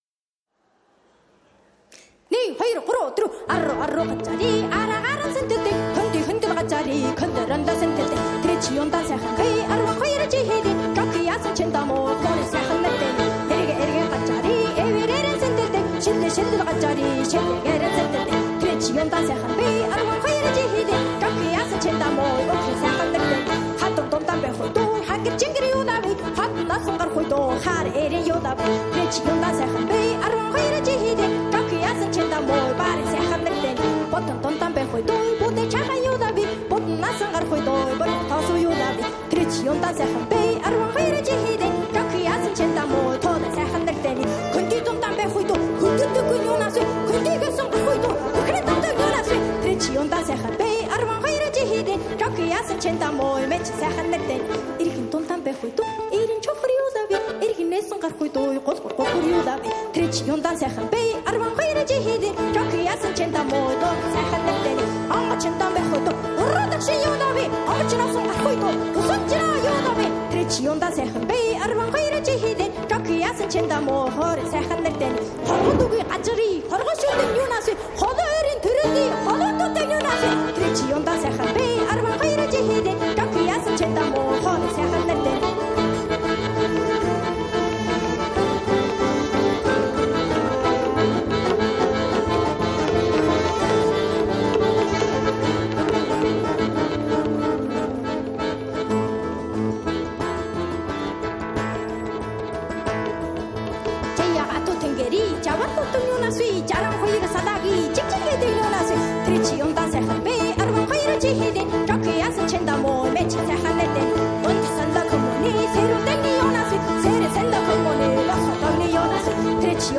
那就是Live現場的魅力。